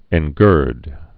(ĕn-gûrd)